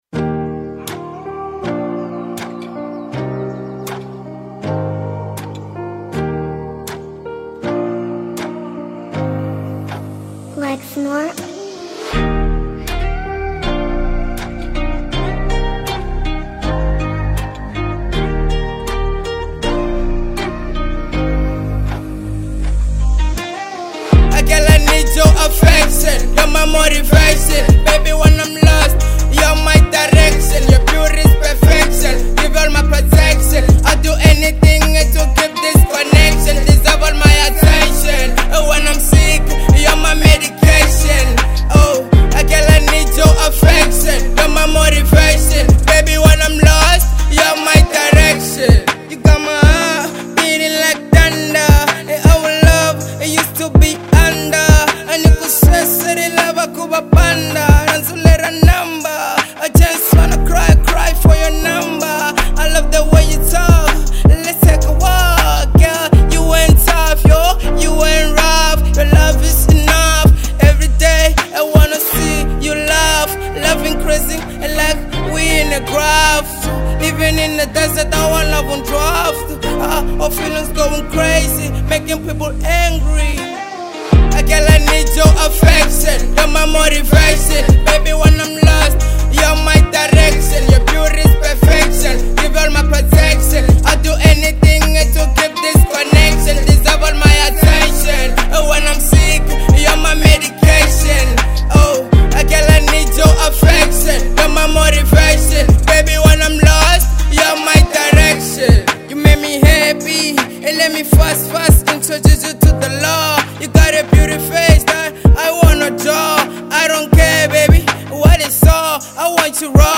02:59 Genre : RnB Size